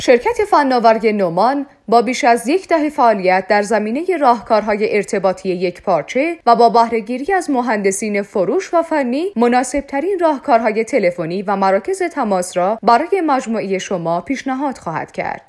صدای خانم تلفن گویا